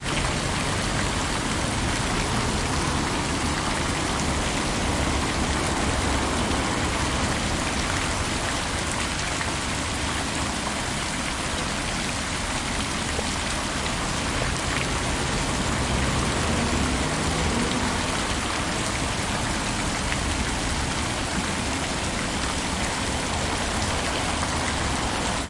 City ambience sounds Bristol " ambience fountain stream city traffic
描述：喷泉有交通和人民背景。
Tag: 音景 环境 街道 现场录音 城市